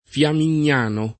[ f L amin’n’ # no ]